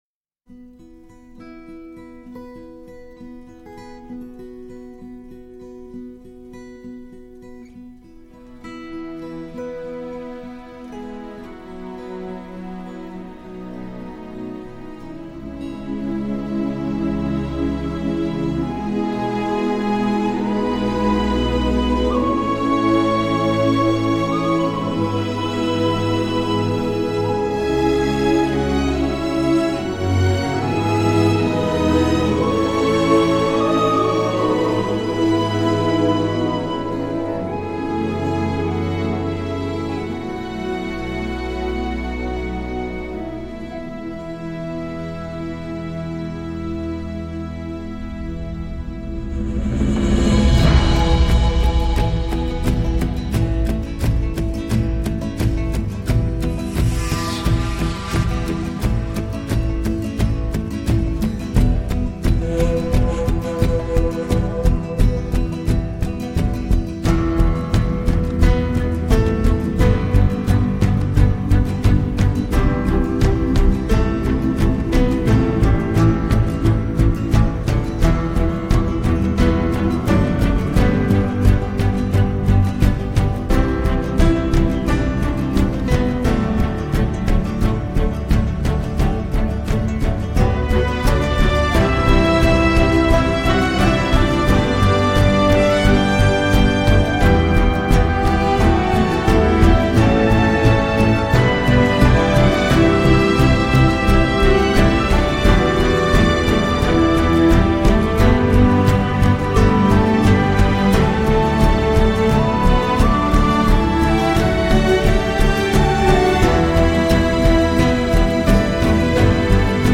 Avec une pointe d’americana et une touche de celtique